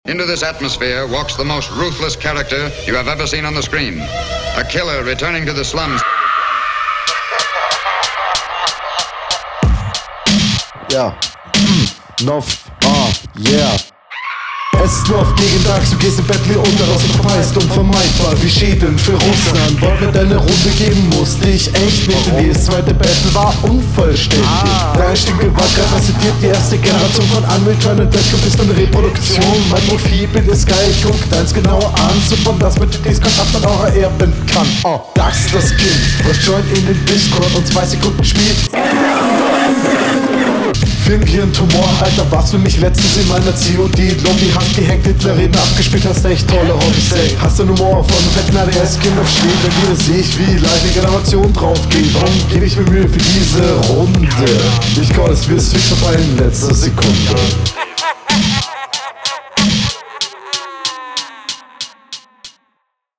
Beat hat was auch wenn die Qualität Schwanz lutscht.